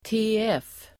Ladda ner uttalet
tf förkortning, pro tem. Uttal: [²t'il:förå:r_dnad (el. ²t'e:ef:)] Variantform: även tf. Definition: tillförordnad (att tillfälligt sköta en tjänst) Exempel: tf professor (professor pro tem.) pro tem. förkortning, tf Förklaring: tillförordnad (att tillfälligt sköta en tjänst) Exempel: professor pro tem.